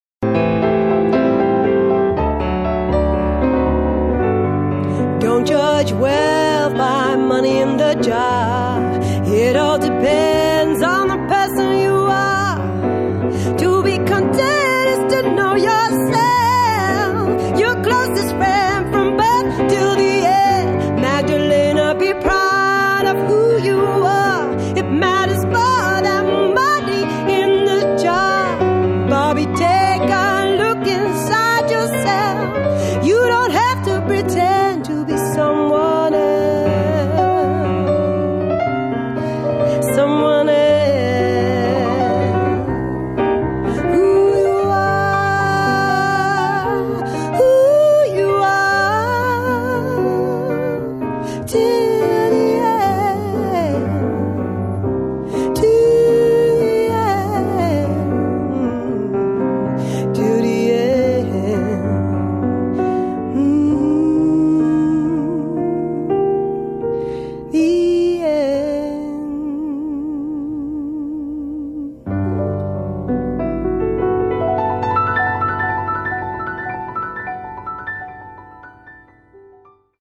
[ SOUL / FUNK / LATIN ]